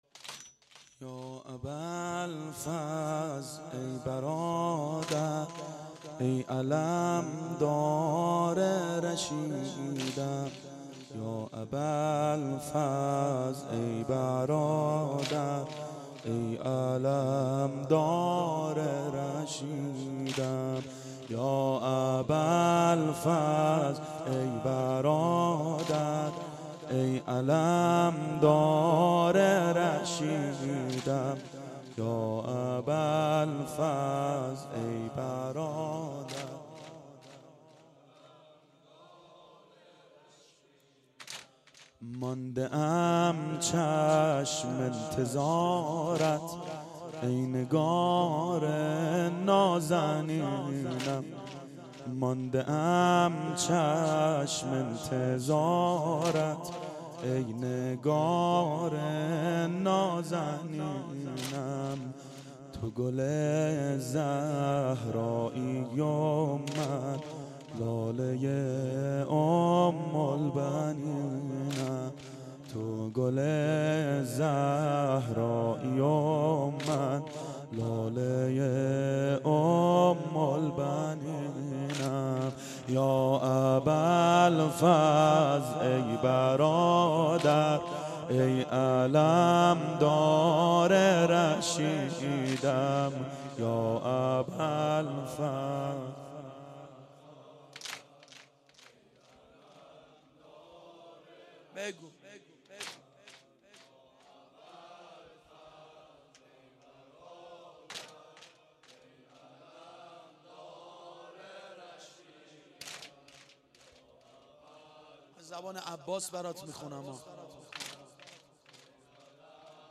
شب تاسوعا 92 هیأت عاشقان اباالفضل علیه السلام منارجنبان